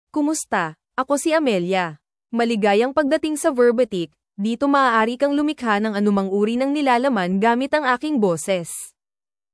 AmeliaFemale Filipino AI voice
Amelia is a female AI voice for Filipino (Philippines).
Voice sample
Listen to Amelia's female Filipino voice.
Amelia delivers clear pronunciation with authentic Philippines Filipino intonation, making your content sound professionally produced.